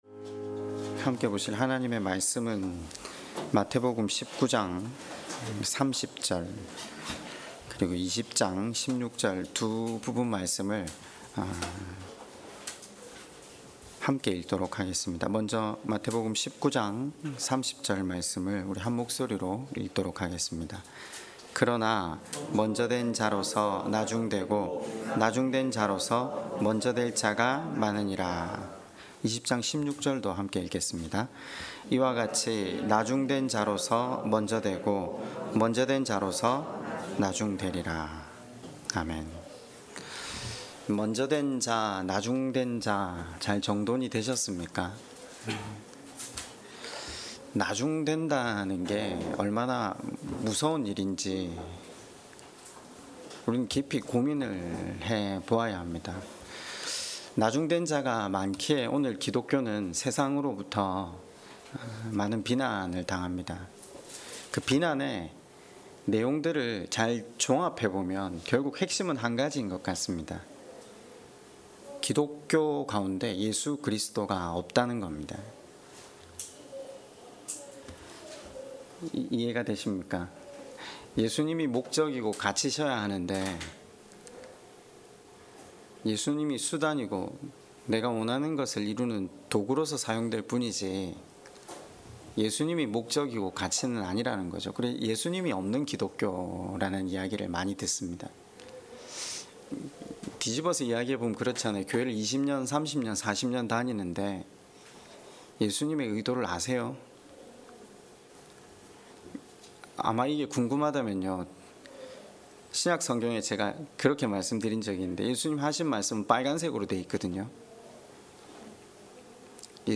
설교 - 2023년 01월 22일 마태복음 22장 1-14절 (예복을 입고 잔치에 참여한다는 것(준비할 예복은 무엇인가))